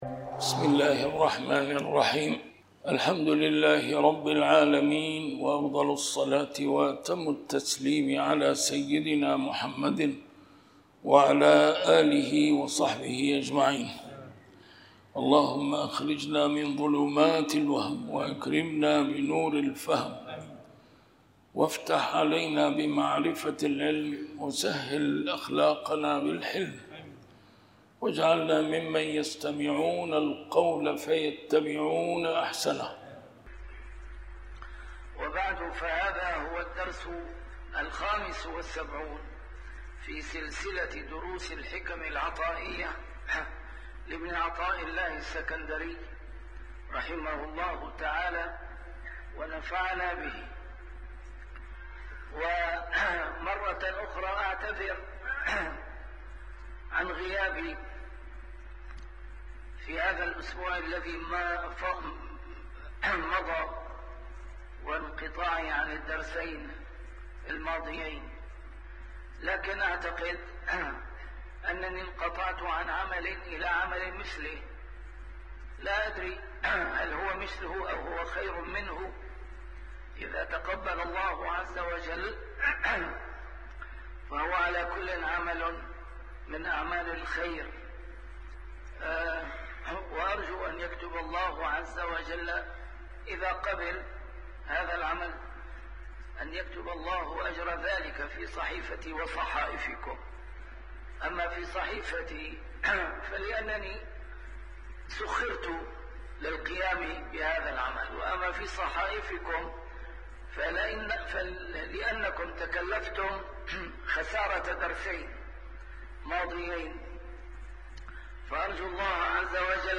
A MARTYR SCHOLAR: IMAM MUHAMMAD SAEED RAMADAN AL-BOUTI - الدروس العلمية - شرح الحكم العطائية - الدرس رقم 75 شرح الحكمة 52-54